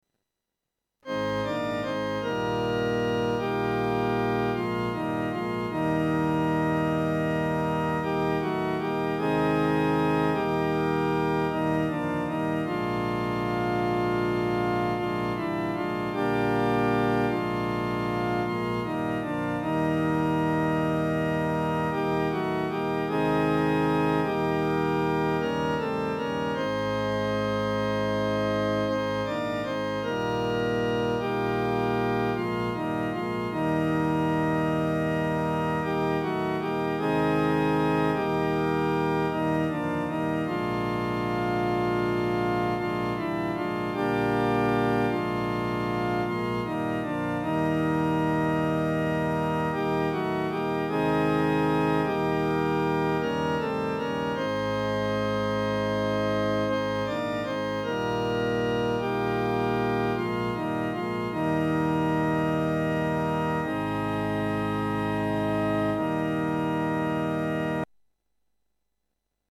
男低